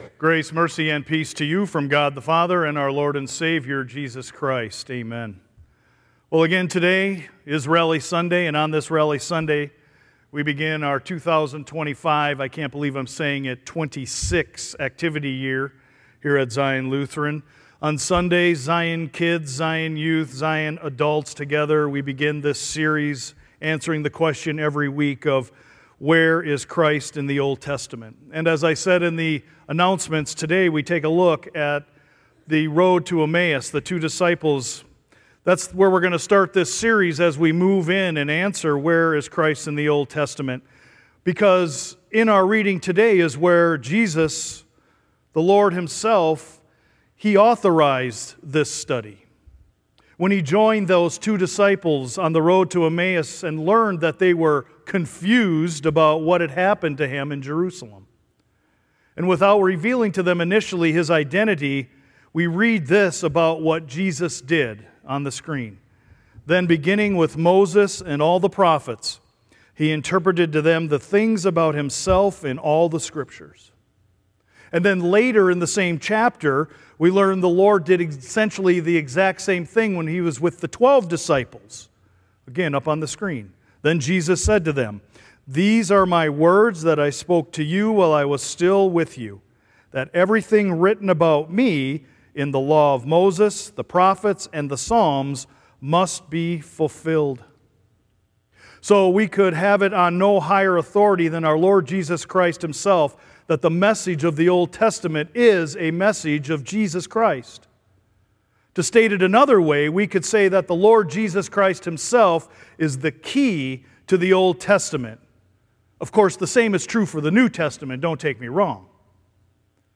Sermon-Sept-7.mp3